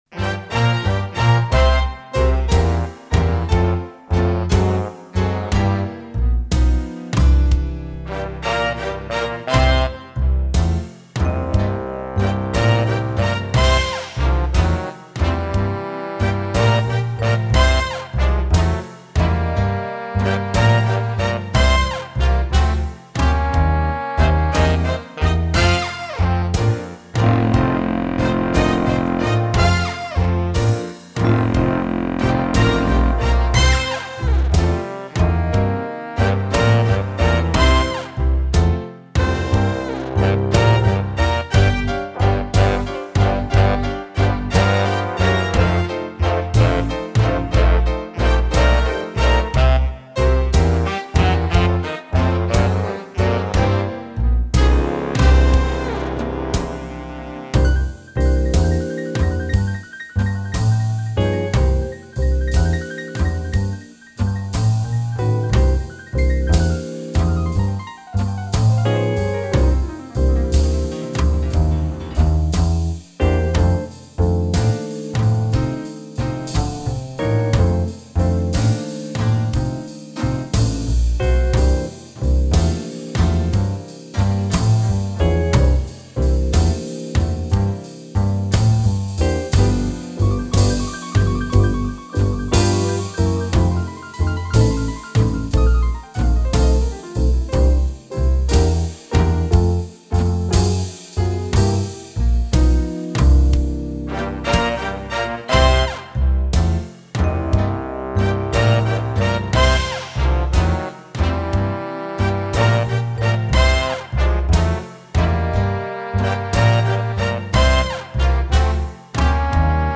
V - IV - I - V
Blues en Do.